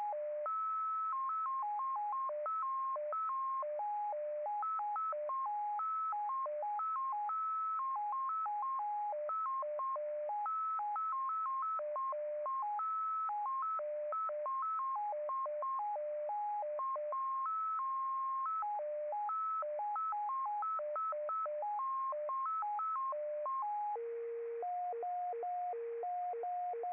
Over the air test sample